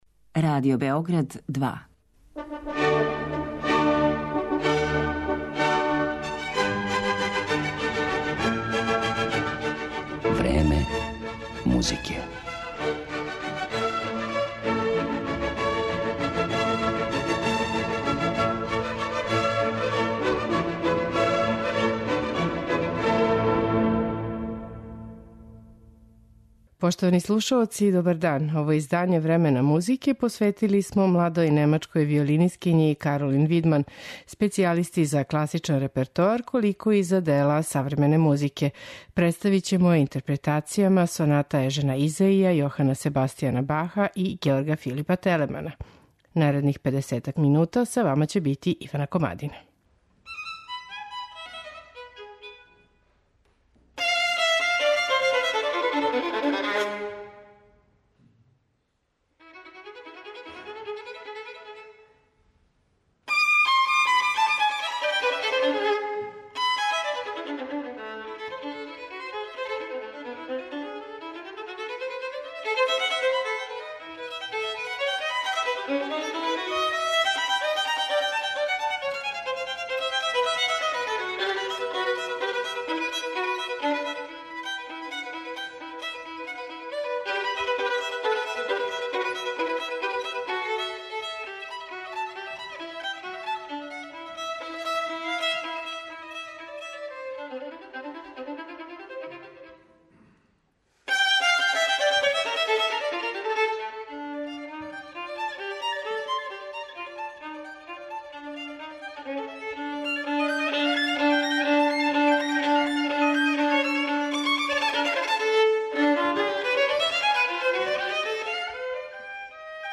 млада немачка виолинисткиња